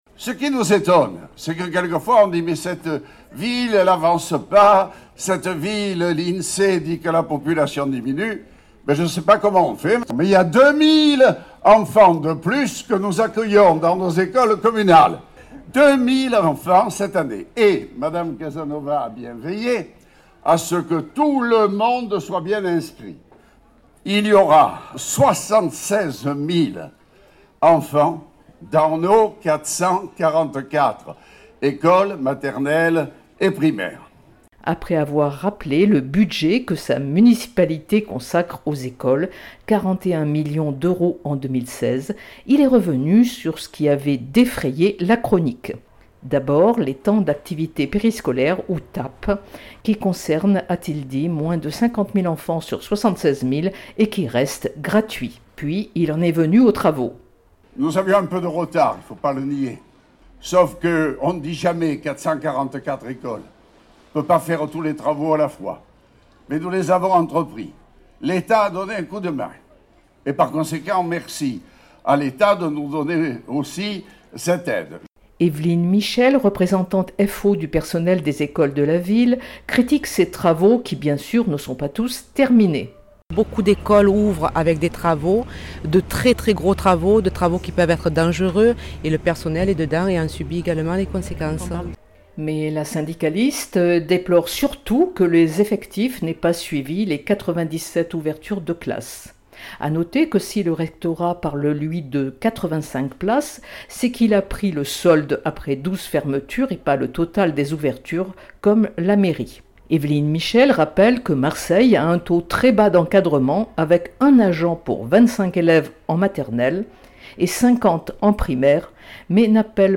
Reportages